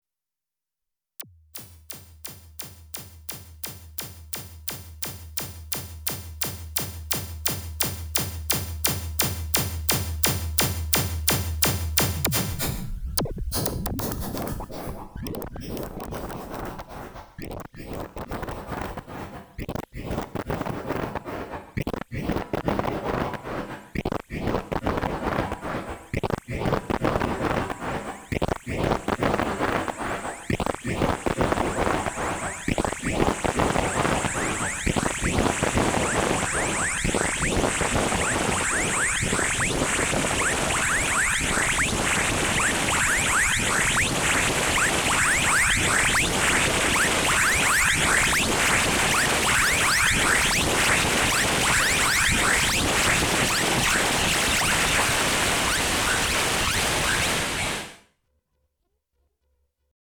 Now with a ping!
I press a key once to send a UT Impulse with a very fast and short filter envelope at max resonance to FX.
Let it build up in the delay feedback a moment, then start the sequencer where the FX block has the delay time locked at different speeds until it gets out of hand.